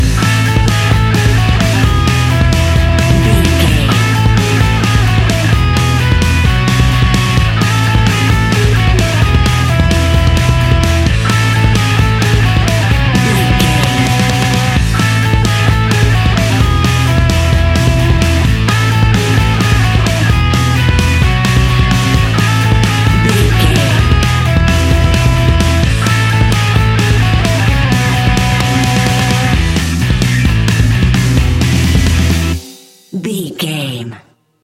Ionian/Major
energetic
driving
aggressive
electric guitar
bass guitar
drums
hard rock
distortion
punk metal
heavy drums
distorted guitars
hammond organ